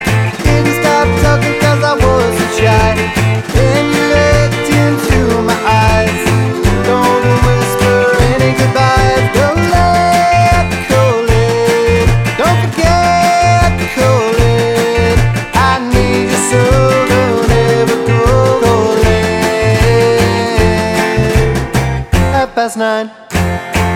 No Harmony Pop (1960s) 1:52 Buy £1.50